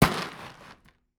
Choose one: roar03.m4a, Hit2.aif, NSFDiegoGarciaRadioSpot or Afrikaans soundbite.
Hit2.aif